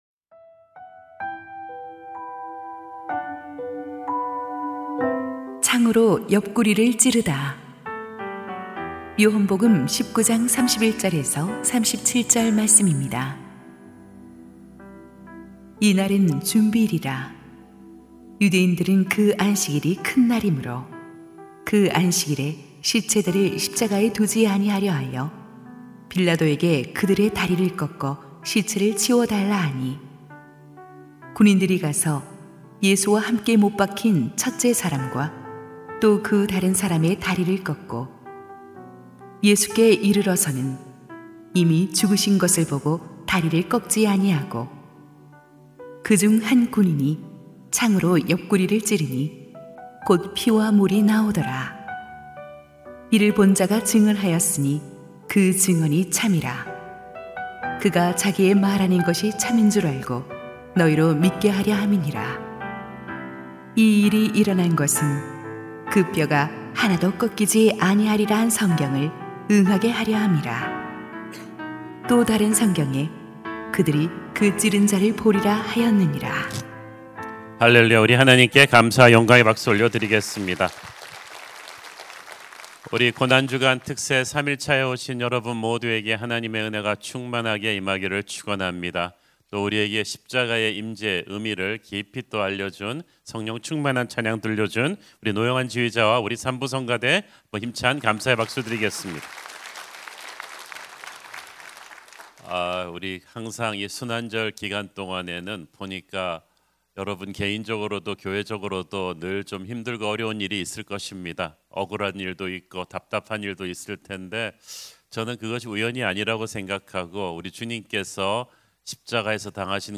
> 설교
[새벽예배]